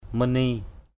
/mə:n-ni:/ (d.) máu (ngôn ngữ thần bí) = sang (lang. mystique). blood (mystical language).